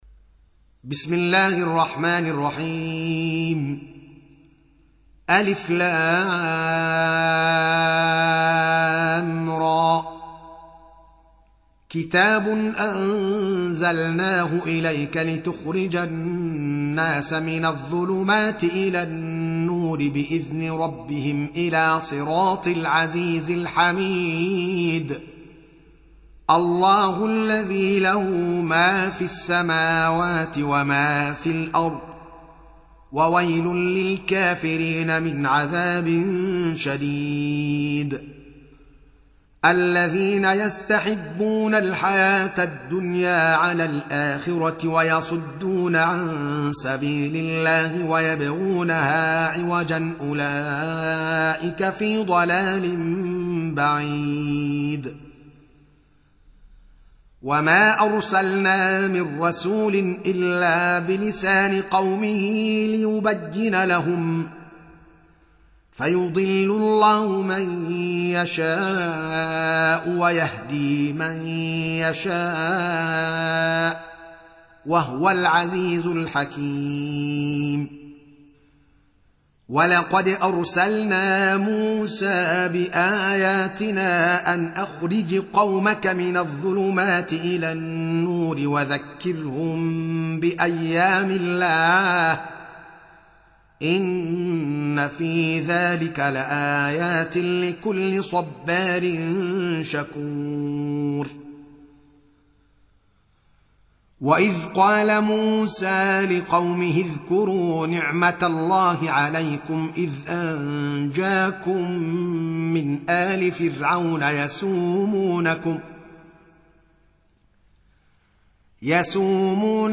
Surah Sequence تتابع السورة Download Surah حمّل السورة Reciting Murattalah Audio for 14. Surah Ibrah�m سورة إبراهيم N.B *Surah Includes Al-Basmalah Reciters Sequents تتابع التلاوات Reciters Repeats تكرار التلاوات